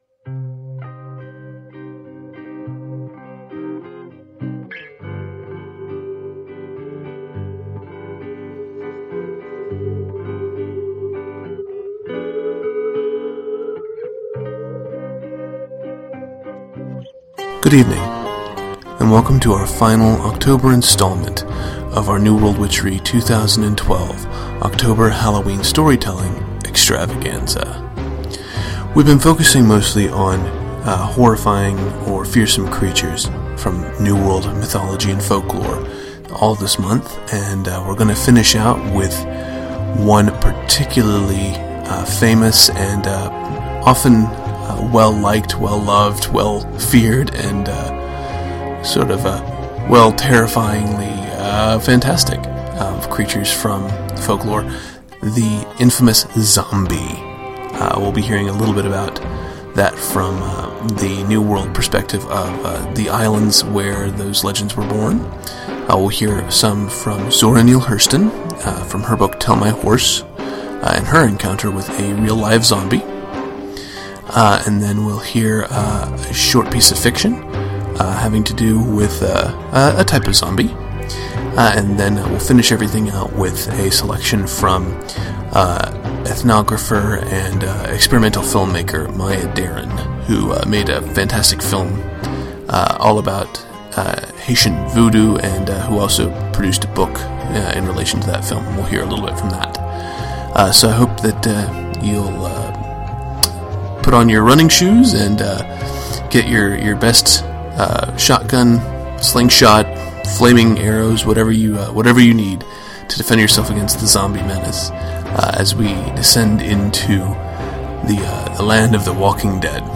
Apologies for the audio glitches near the middle of the episode!